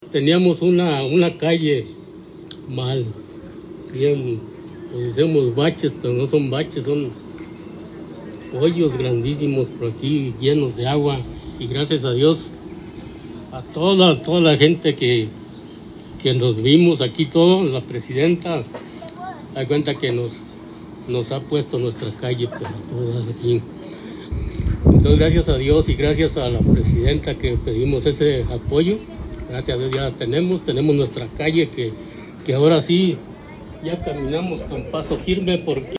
habitante de la comunidad